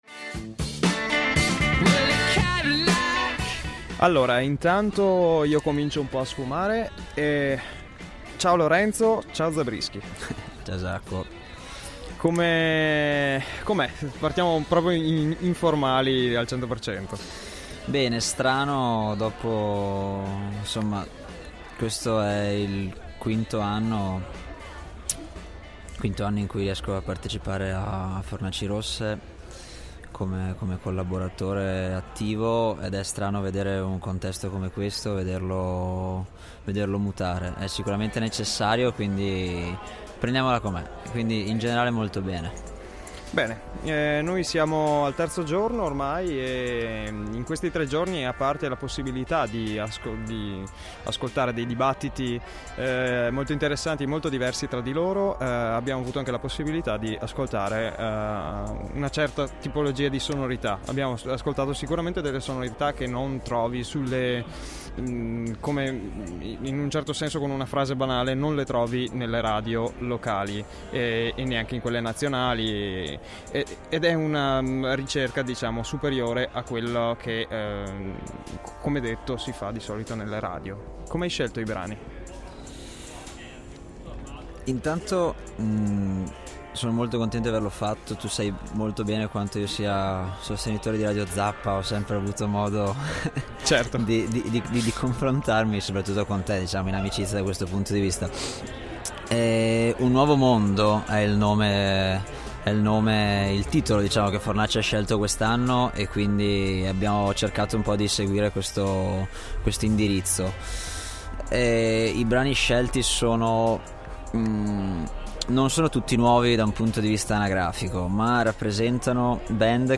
LE INTERVISTE DI RADIO ZAPPA
Un’edizione ridotta di Fornaci Rosse ma sostenuta e amplificata dai nostri microfoni.